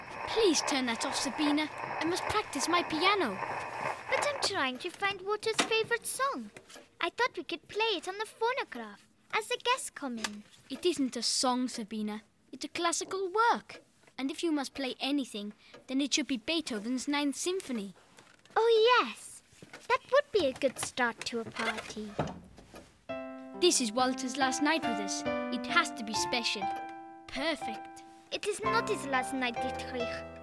What are you willing to pay That’s the question explored in “Bonhoeffer: The Cost of Freedom.” Chronicling the life of German theologian Dietrich Bonhoeffer, this provocative Peabody award-winning drama.